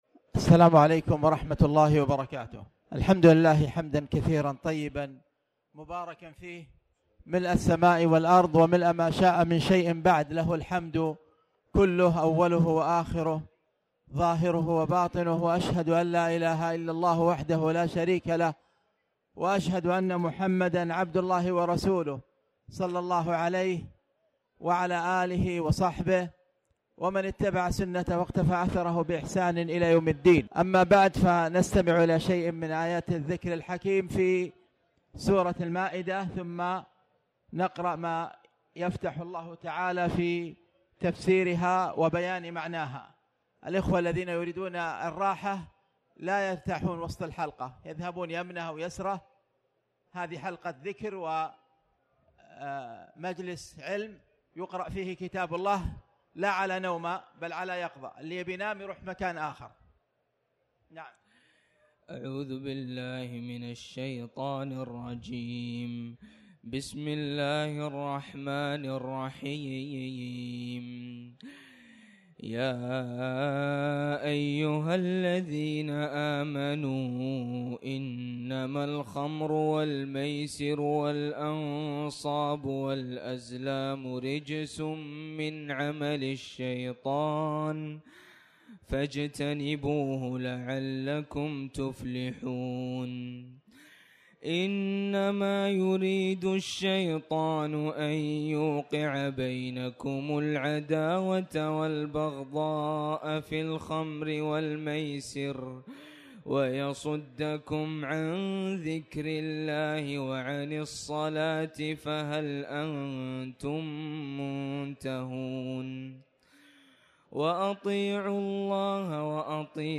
تاريخ النشر ٢١ رمضان ١٤٣٩ هـ المكان: المسجد الحرام الشيخ